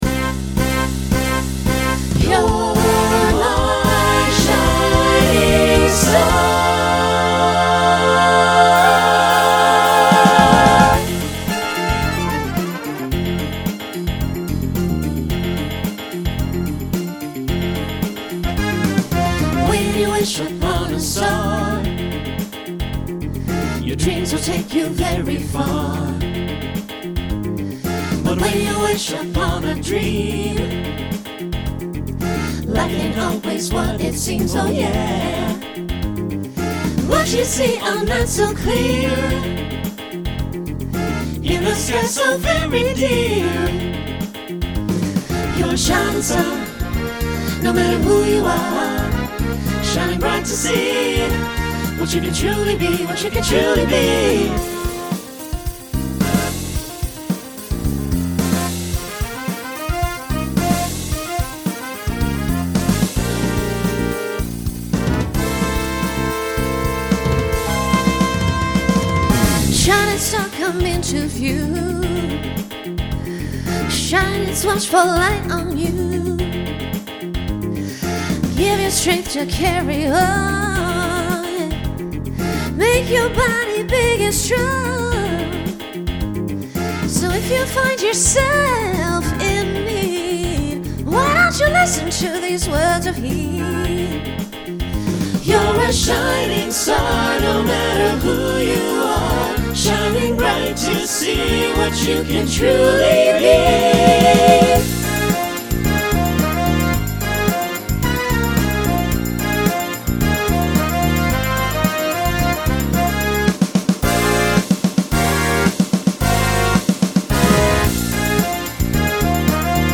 Disco , Pop/Dance Instrumental combo
Voicing SATB